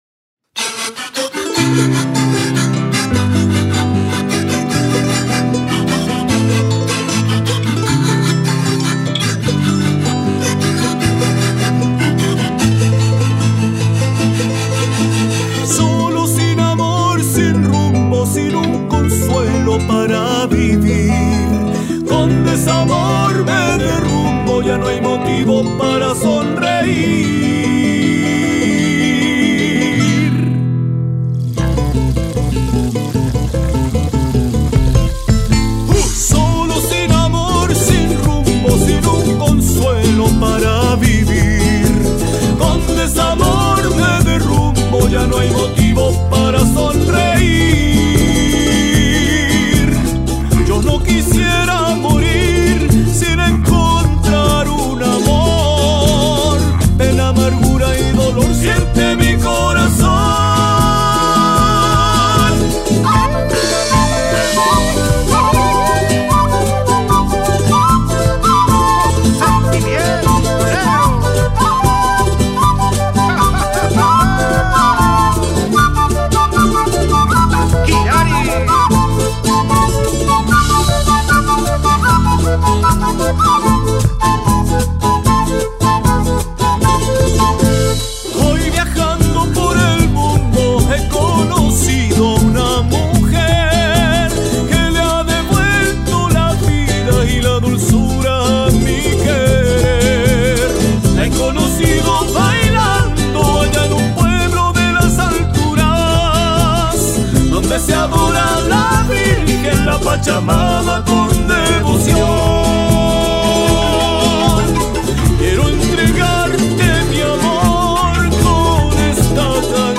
una propuesta en estilo taquirari que aborda la esperanza de encontrar el amor.